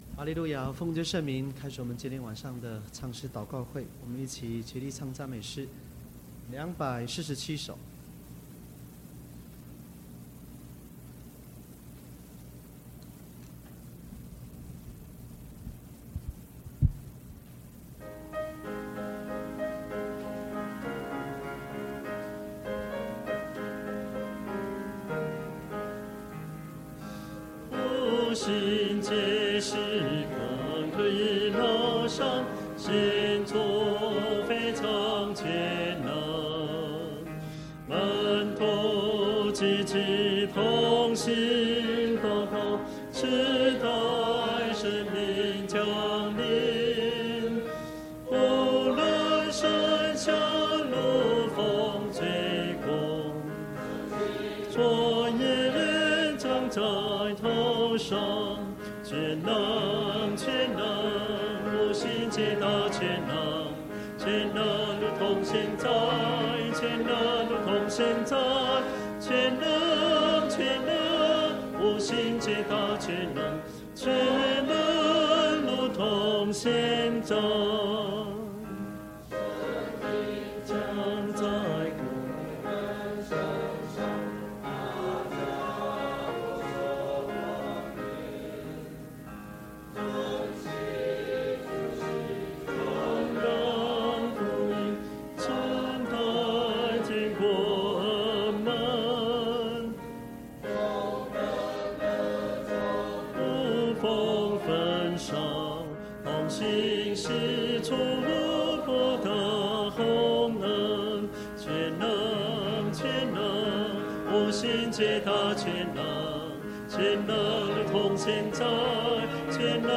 2014年秋季靈恩佈道會 聚會錄音mp3合輯